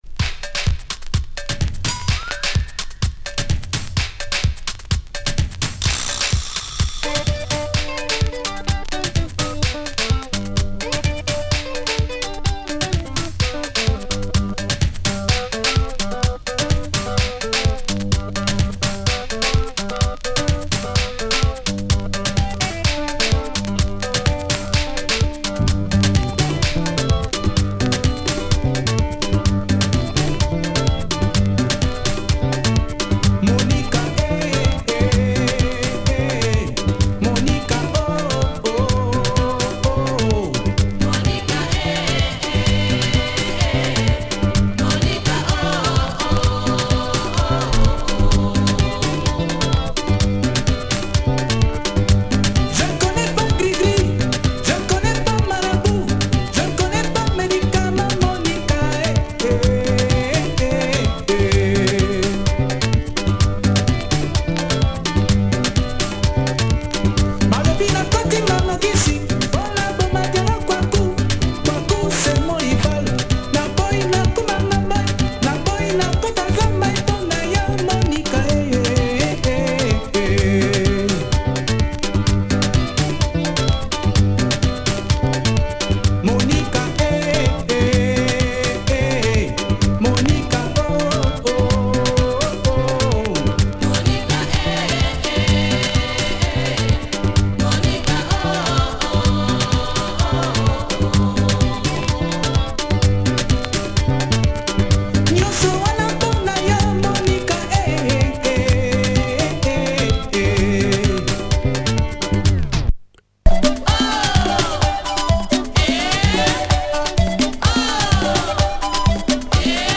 Nice 80’s Lingala track with modern keyboard arrangements.